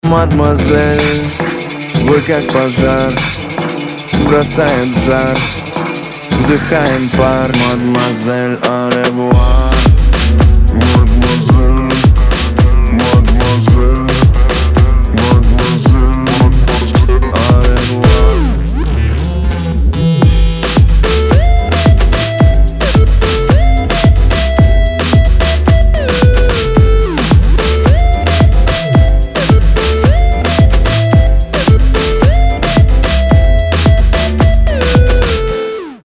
• Качество: 320, Stereo
мужской вокал
Хип-хоп
dance
club